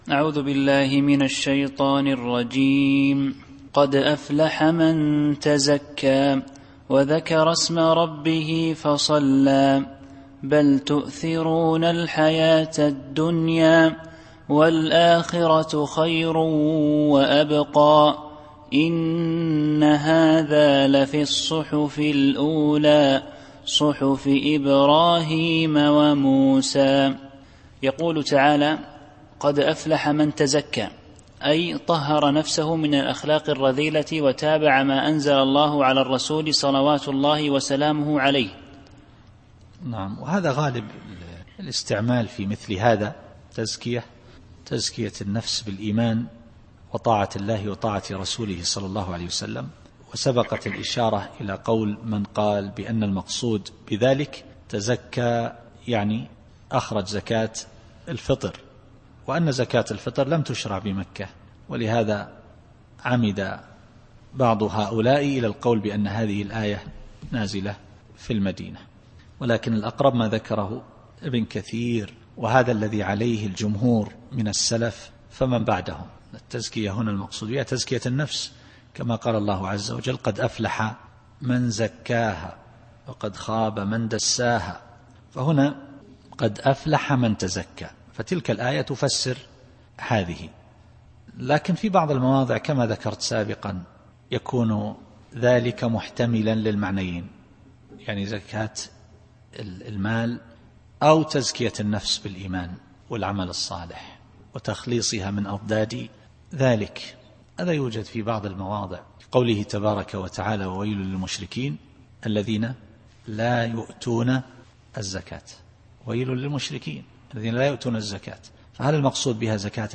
التفسير الصوتي [الأعلى / 14]